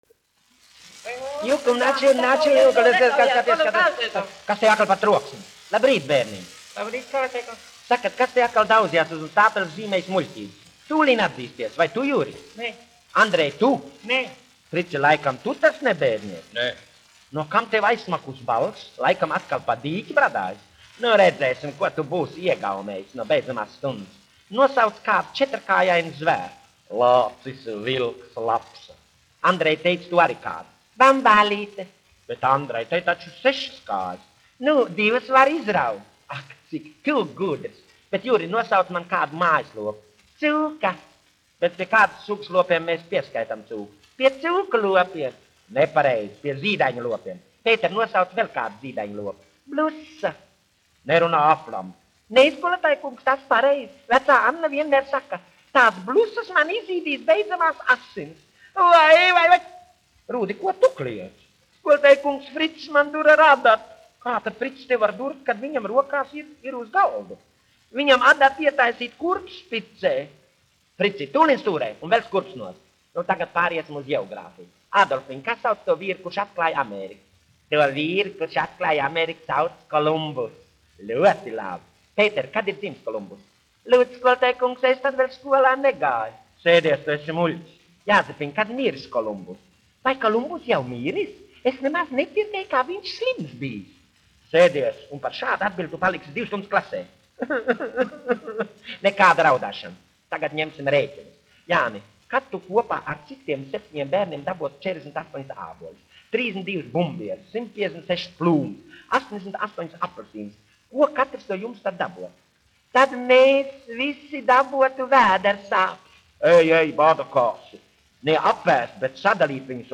Vecais pagasta skolotājs : solo skats
1 skpl. : analogs, 78 apgr/min, mono ; 25 cm
Skaņuplate